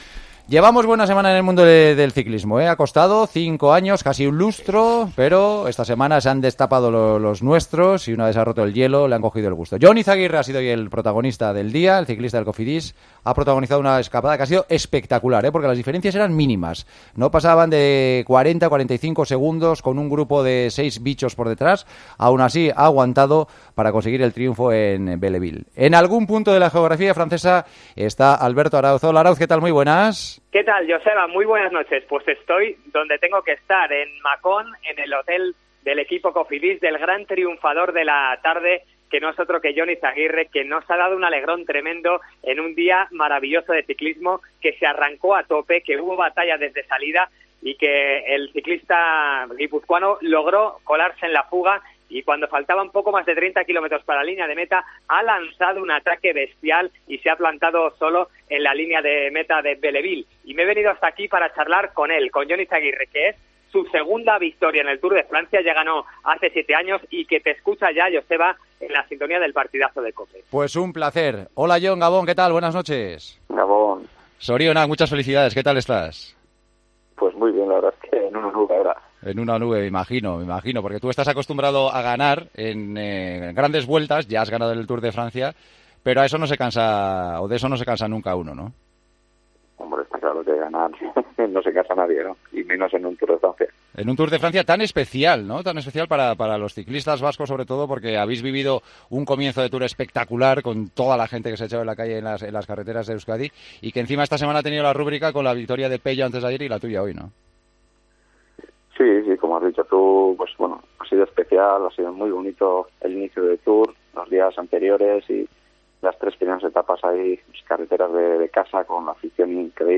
El ciclista del Cofidis ganó en la 12ª etapa del Tour de Francia y la analizó en El Partidazo de COPE.